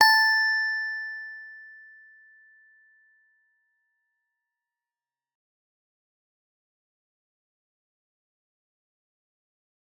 G_Musicbox-A5-f.wav